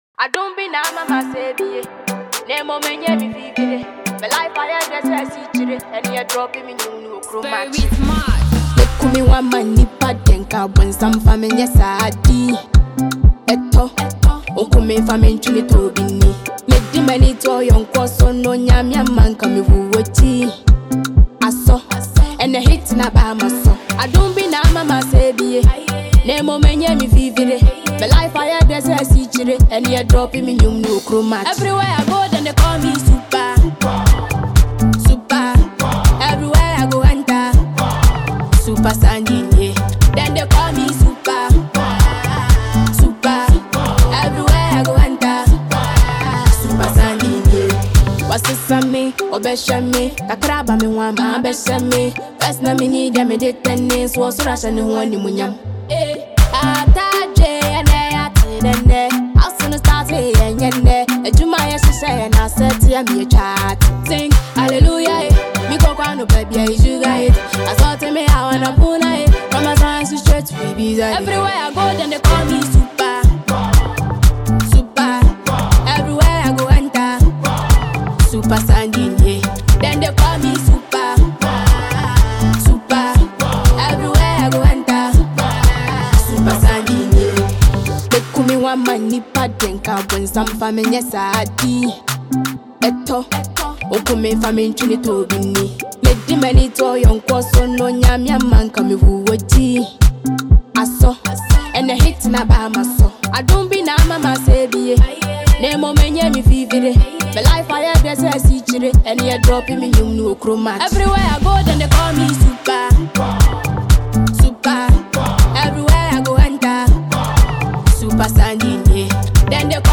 • Genre: Afrobeat